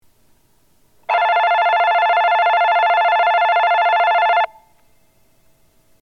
（電子電鈴）
発車ベル
4秒鳴動です。電子電鈴から発車ベルが流れます。